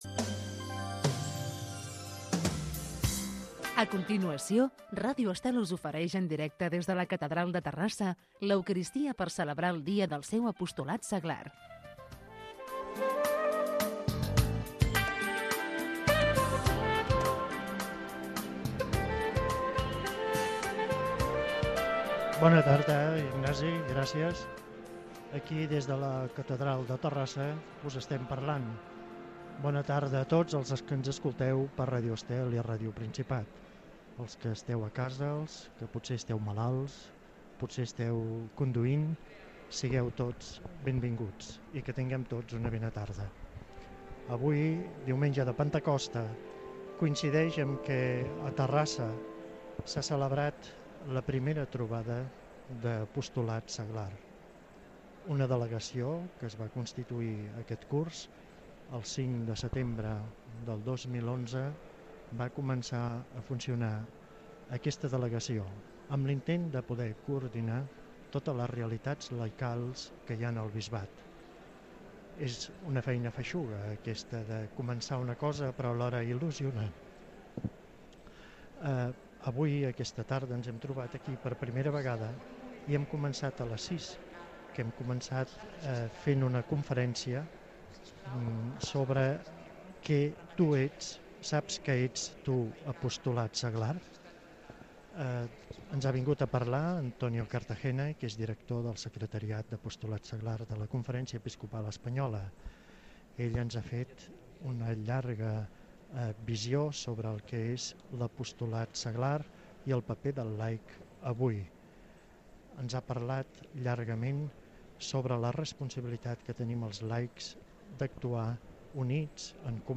Transmisisó de la missa del diumenge de Pentacosta des de la catedral de Terrassa, comentari sobre l'apostolat seglar i inici de la celebració de la missa